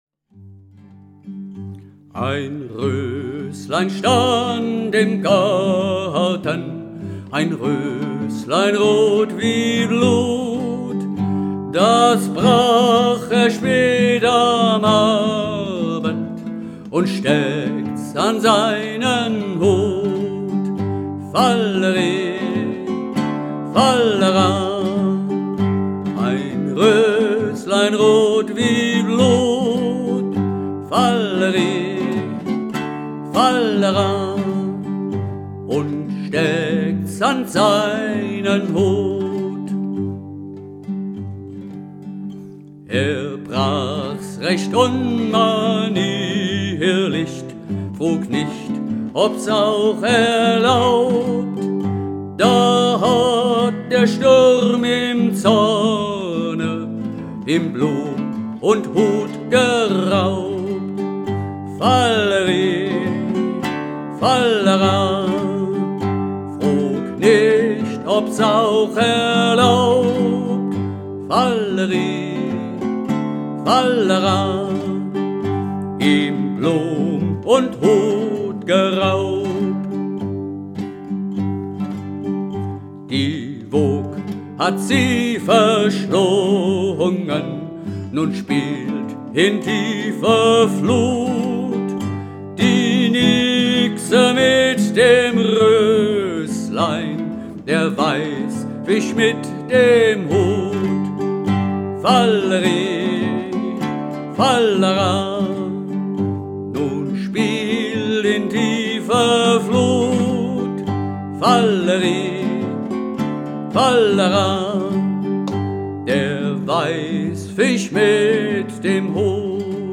4/4 Takt